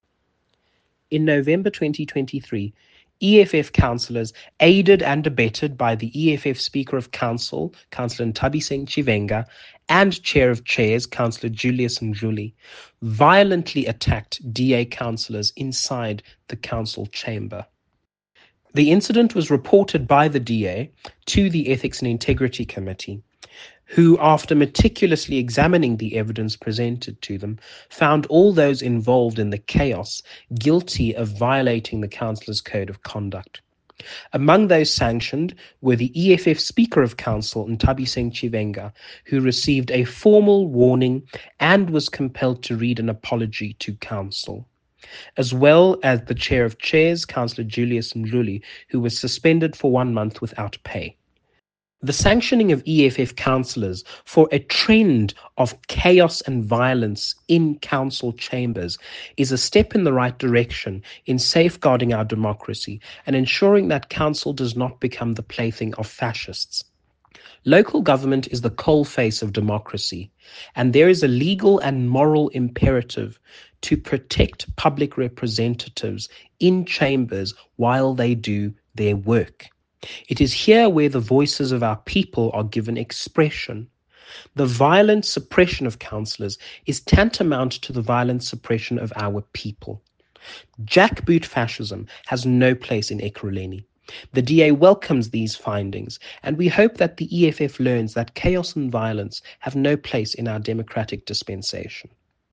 Issued by Cllr Lucky Dinake – DA Ekurhuleni Caucus Chief Whip
Note to Editors: Please find an English soundbite by Cllr Lucky Dinake